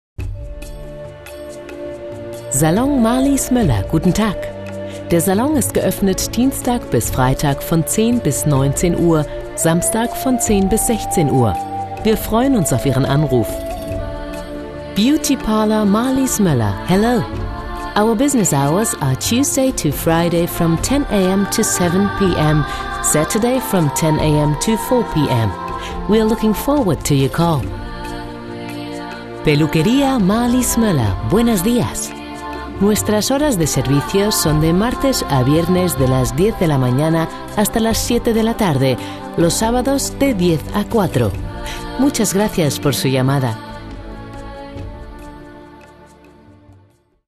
Profi-Sprecherin spanisch.
Sprechprobe: Industrie (Muttersprache):
spanish female voice over artist.